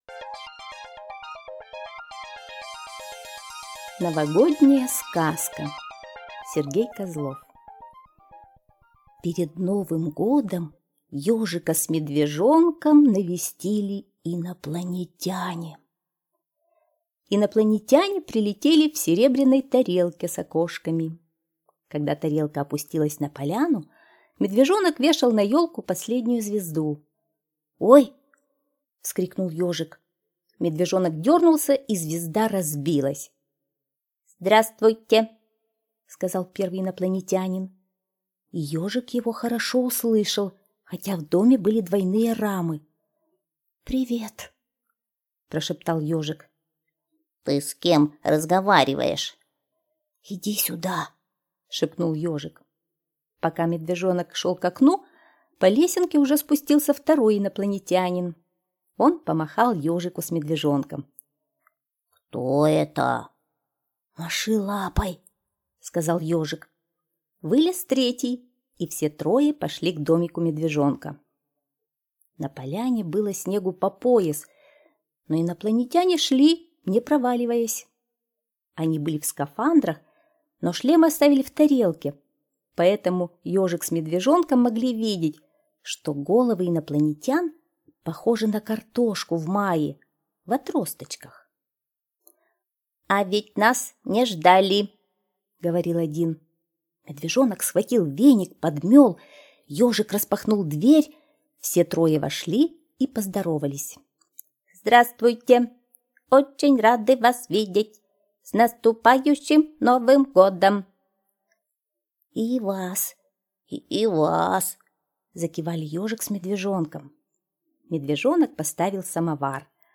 Слушайте Новогодняя сказка - аудиосказка Козлова С.Г. Сказка про то, как к Ежику и Медвежонку на Новый год прилетели в гости инопланетяне.